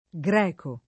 greco [ g r $ ko ], ‑chi